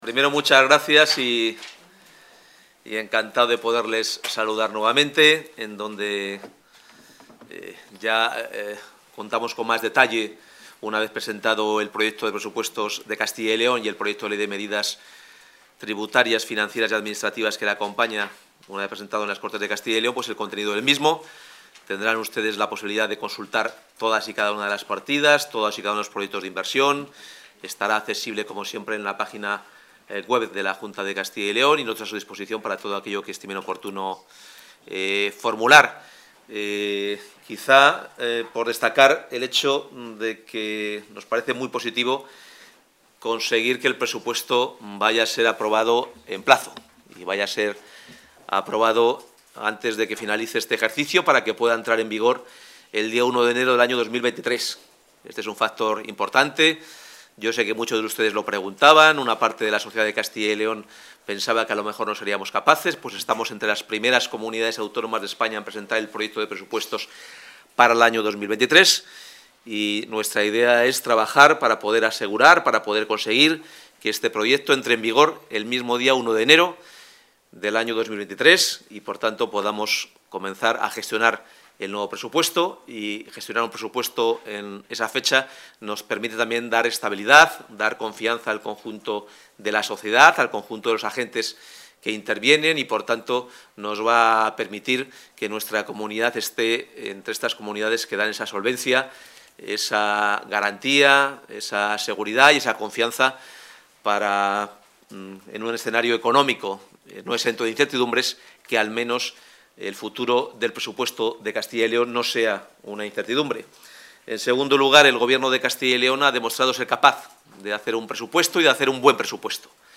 Intervención del consejero de Economía y Hacienda.
El consejero de Economía y Hacienda, Carlos Fernández Carriedo, ha registrado hoy en las Cortes el Proyecto de Presupuestos Generales de la Comunidad de Castilla y León para el año 2023 y posteriormente lo ha analizado en rueda de prensa.